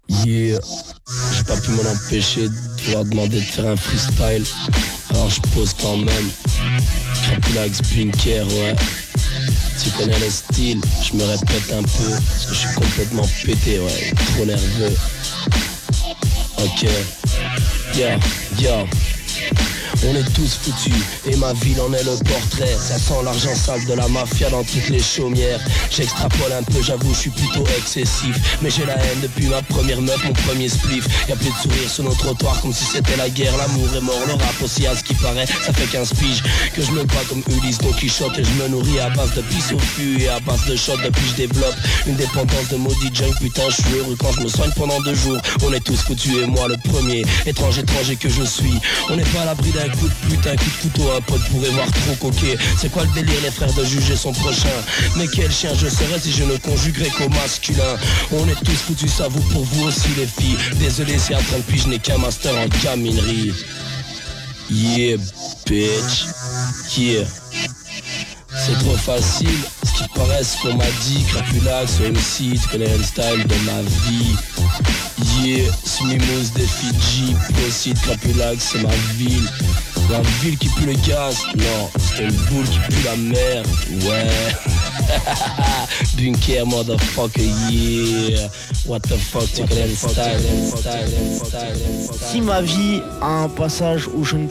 Voor de hiphop-kenners, gisterenavond opgenomen (Shazam herkent het niet):